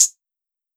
hihat 6.wav